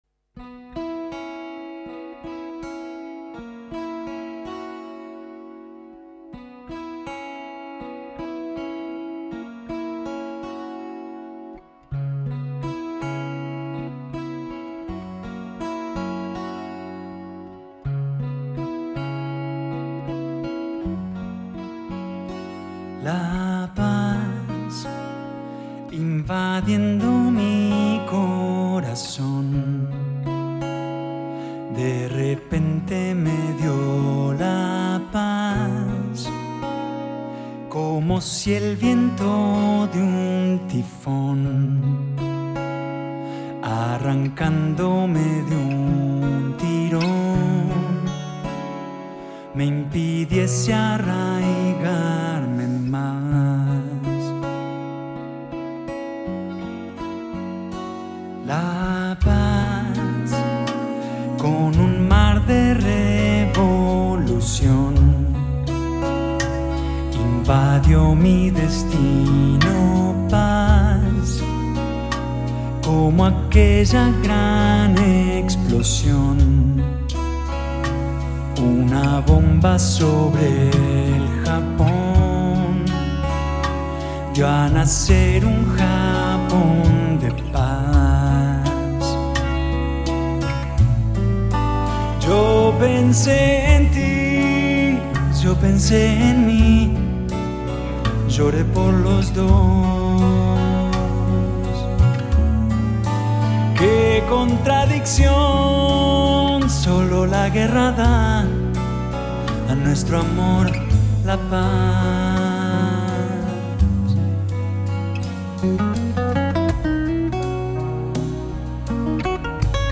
Recorded live in February 2005